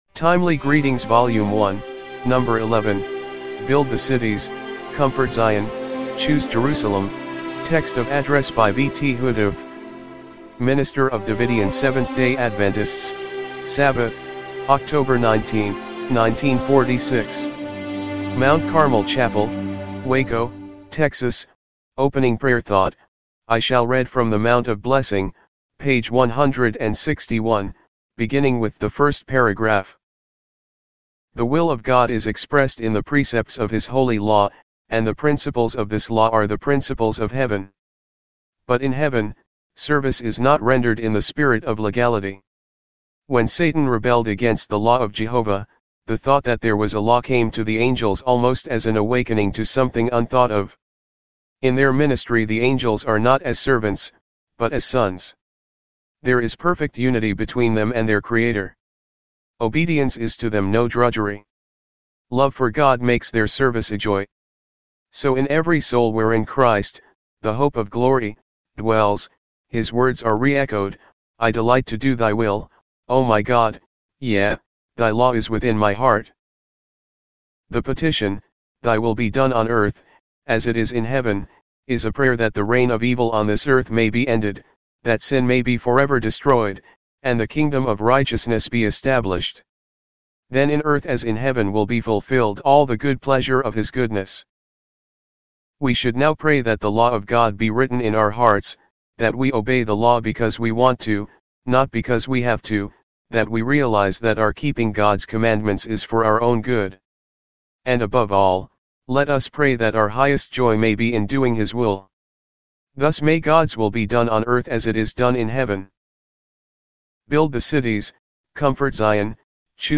timely-greetings-volume-1-no.-11-mono-mp3.mp3